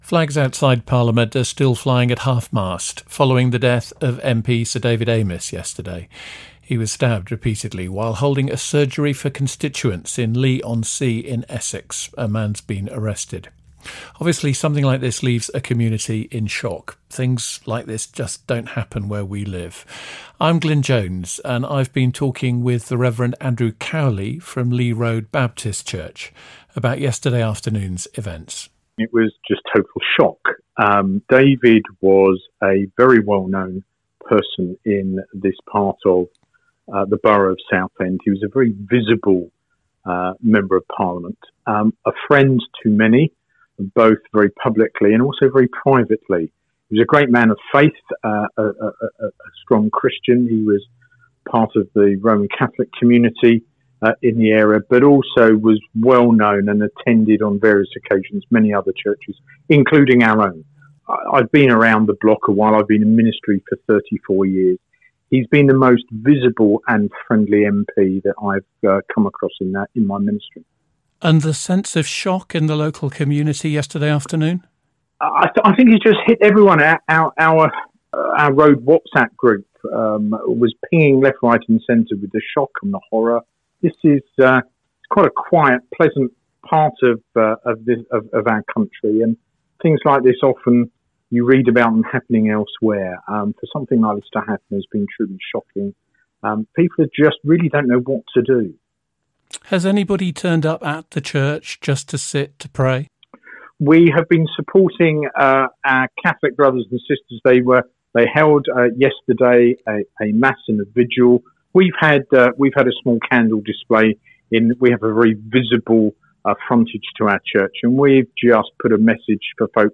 Death of Sir David Amess – interview with a local Baptist minister recorded on the day Sir David Amess died, for broadcast the following morning (October 2021)